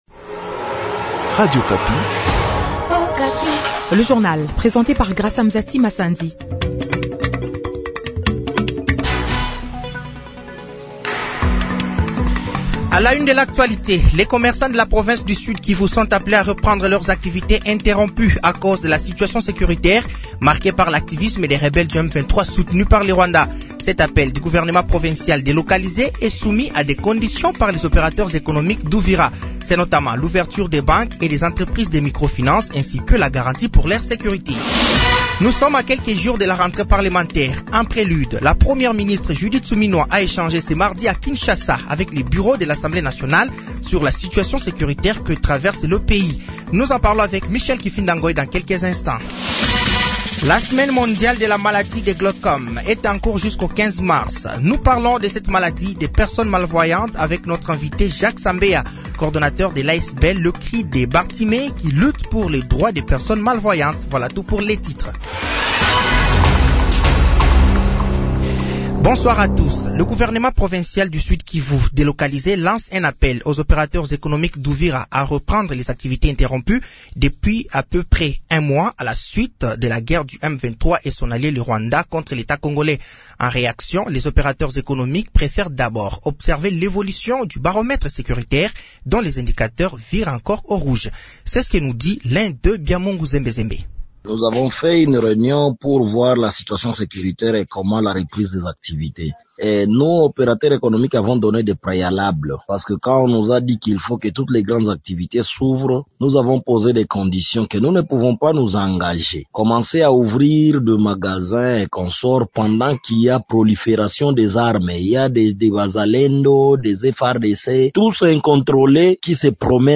Journal français de 18h de ce mercredi 12 mars 2025